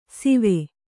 ♪ sive